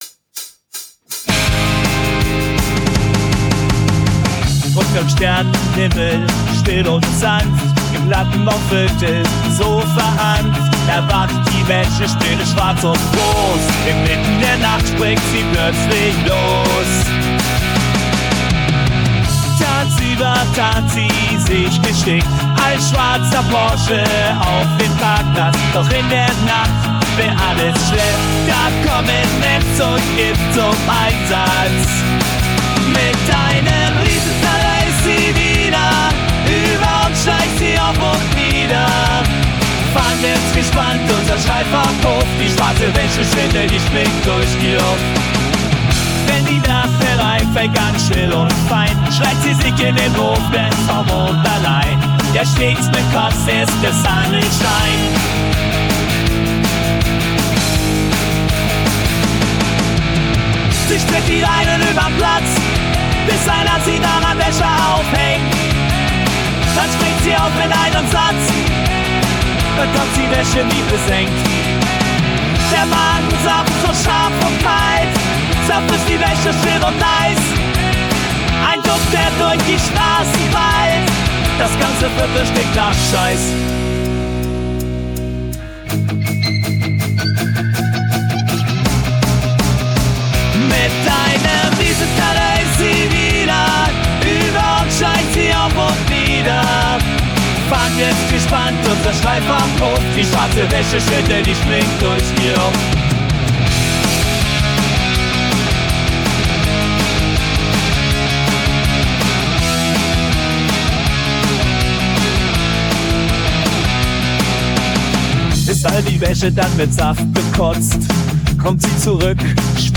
Projekt: KI-unterstütztes Punkalbum